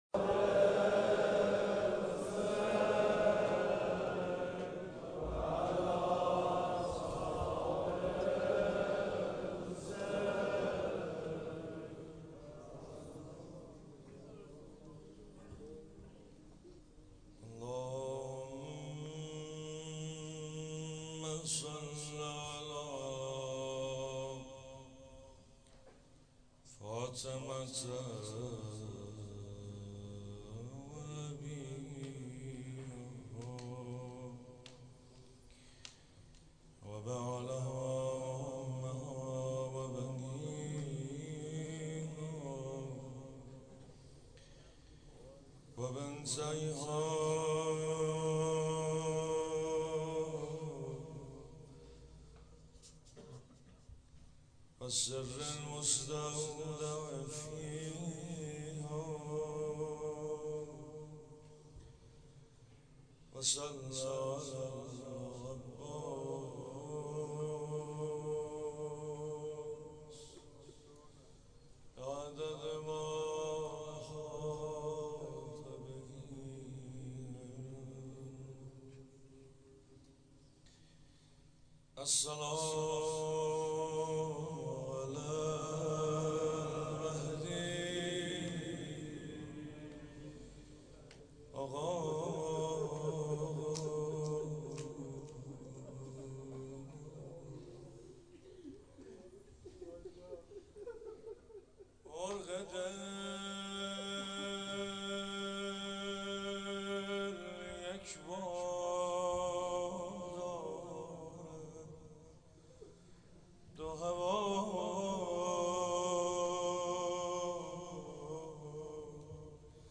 حسینیه حضرت زینب(س)